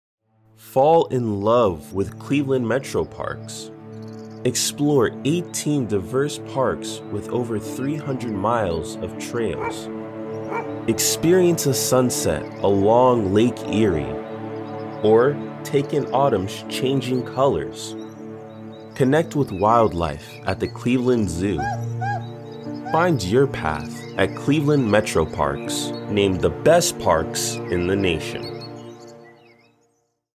Friendly and energetic individual that brings life to all narrations!
Young Adult